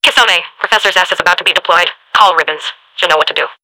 mvm_bomb_alerts09.mp3